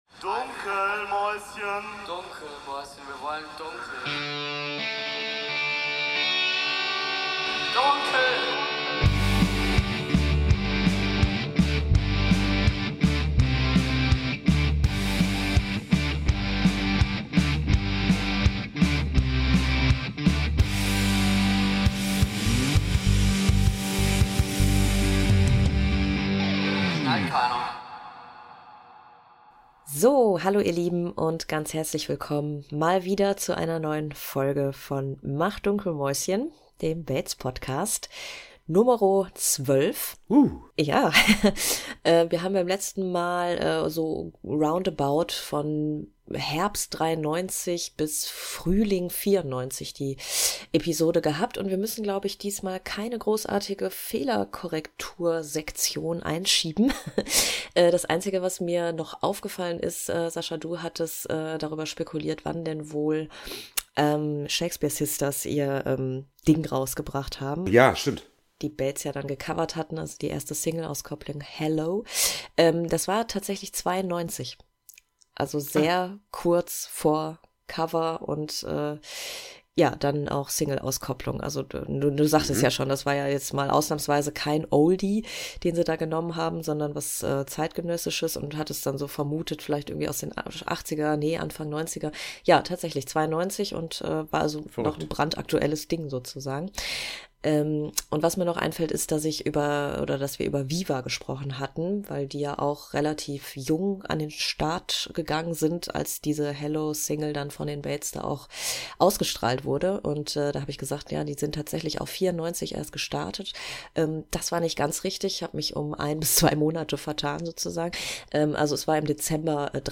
In dieser Episode sprechen wir über die Bubblegum-Trash-Tour und hören einige Eindrucke von Fans, die auf dieser Tour zum ersten Mal die Bates live gesehen haben.